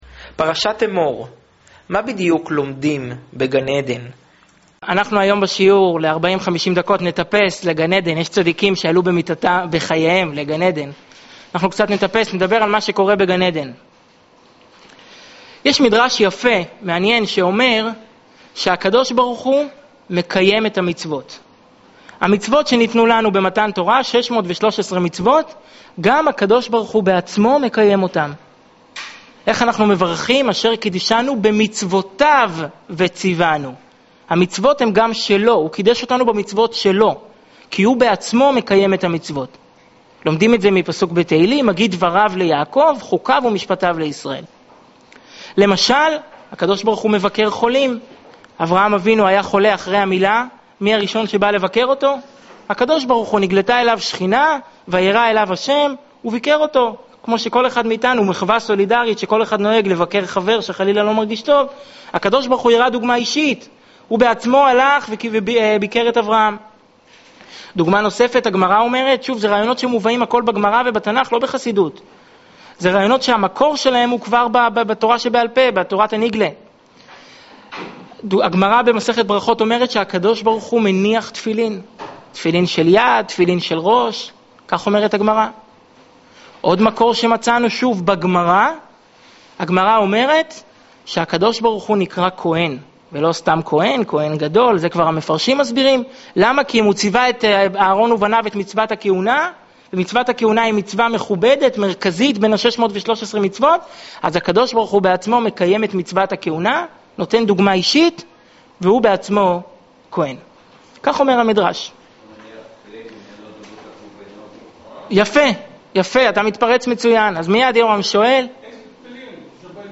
שיעור מאלף לפרשת אמור
שנמסר בבית הכנסת חב"ד בראשון לציון